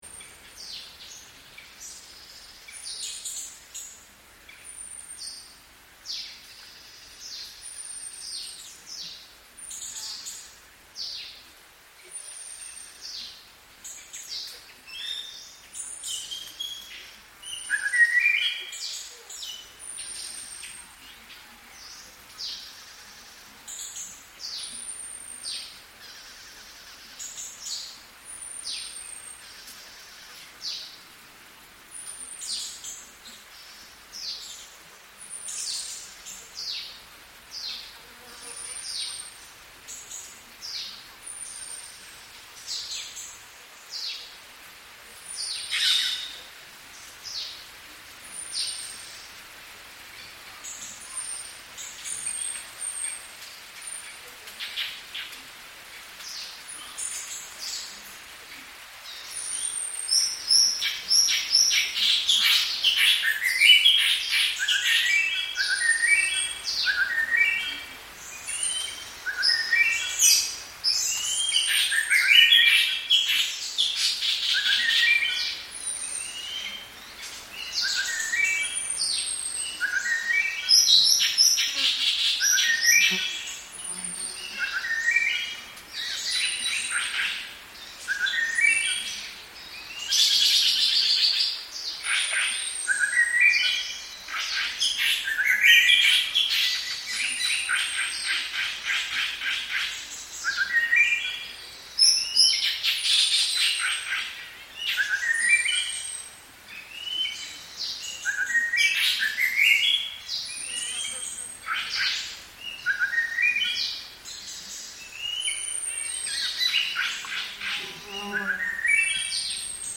A dawn soundscape in the heart of Vietnam’s lowland forest, where the awakening of countless birds marks the start of a new day. The air fills with the diverse chorus of chirps, calls, and rustling, as various species gradually come to life.